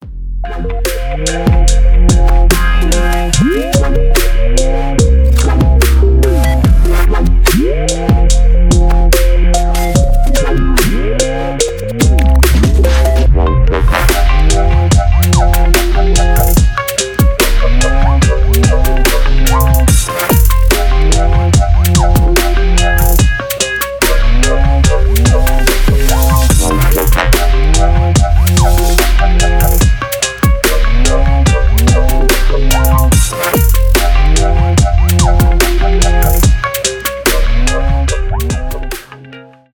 дабстеп , регги , без слов